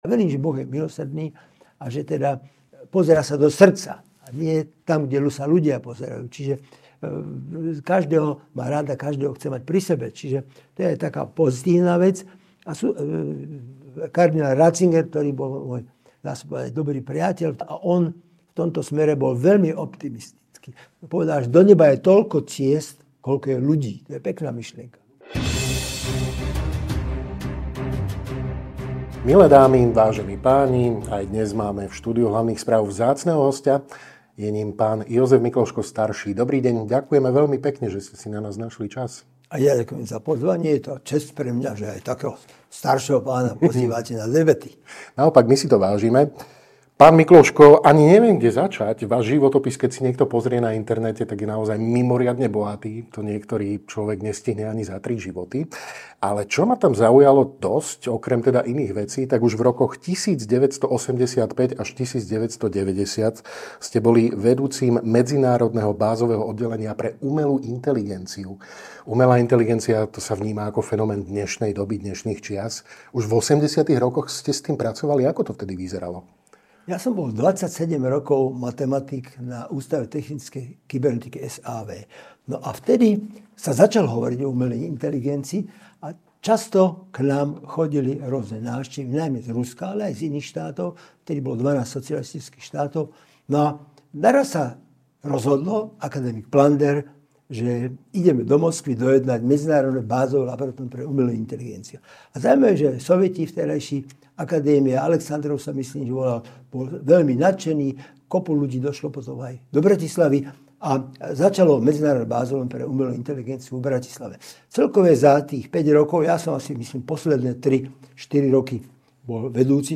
V rozhovore pre Hlavné správy spomína na roky vedy i politiky, hodnotí dnešné KDH a otvorene hovorí aj o etických otázkach. Dotýka sa mediálnej manipulácie, rozdelenia spoločnosti, krízy európskych lídrov a vojny na Ukrajine, ktorú vidí ako dôsledok dlhodobého napätia medzi Východom a Západom.